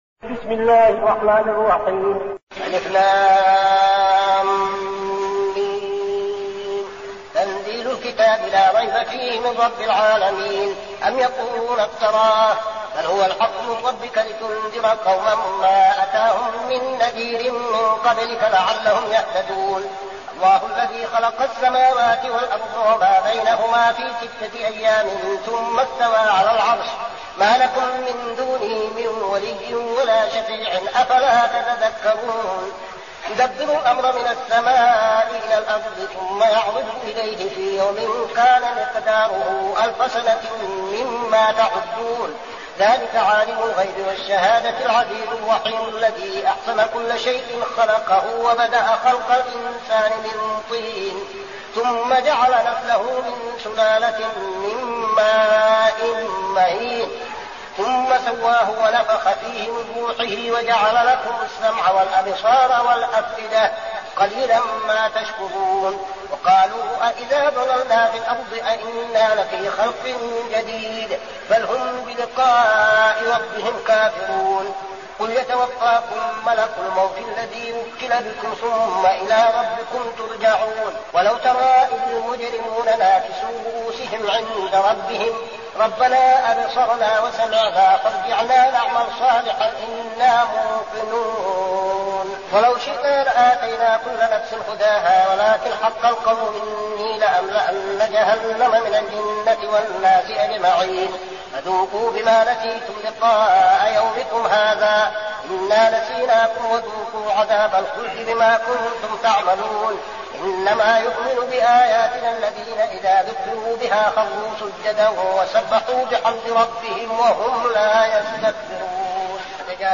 المكان: المسجد النبوي الشيخ: فضيلة الشيخ عبدالعزيز بن صالح فضيلة الشيخ عبدالعزيز بن صالح السجدة